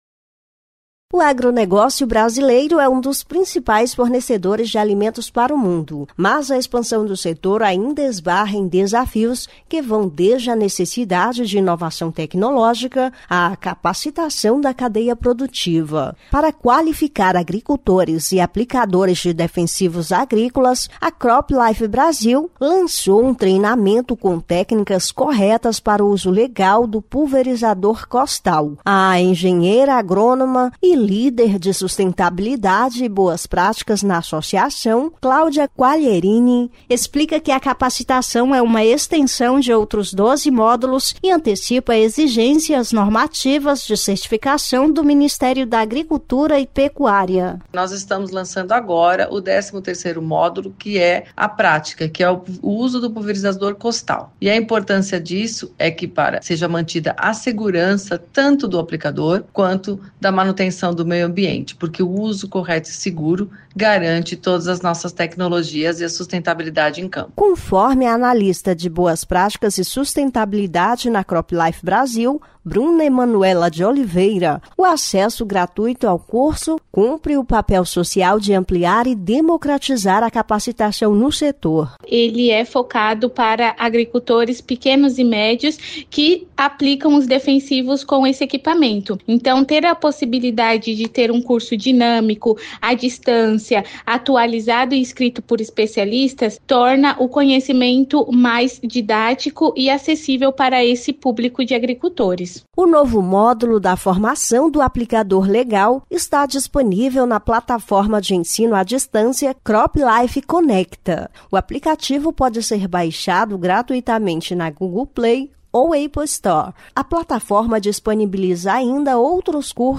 [Rádio] Novo treinamento disponível no Aplicador Legal - CropLife